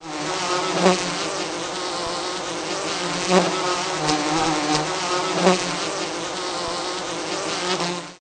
Bees Buzzing, Ambient